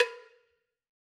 Cowbell1_Hit_v4_rr2_Sum.wav